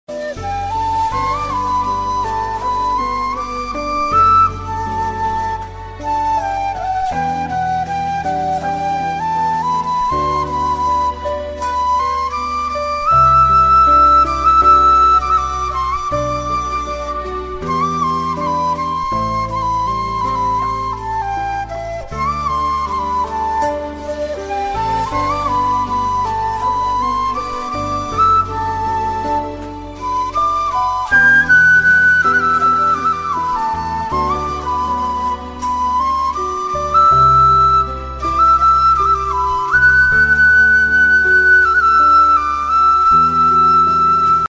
Thể loại nhạc chuông: Nhạc không lời